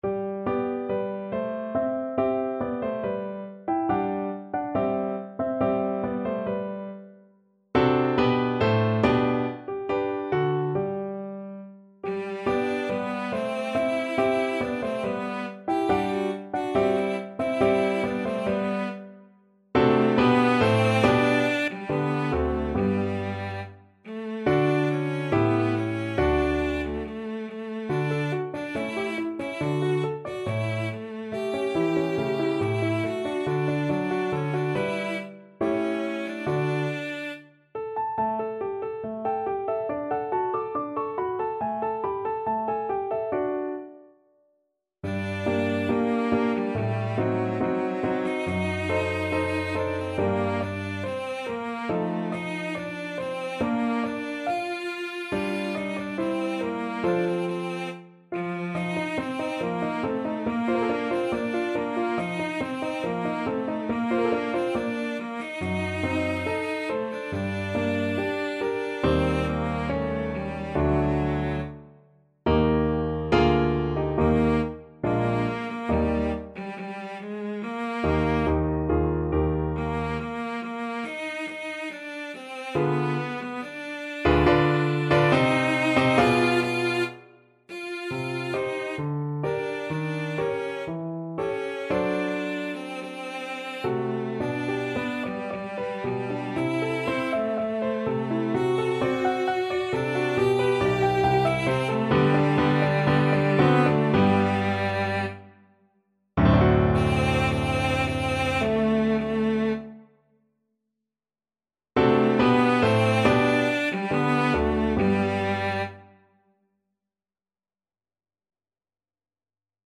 2/4 (View more 2/4 Music)
= 70 Allegretto
F4-G5
Classical (View more Classical Cello Music)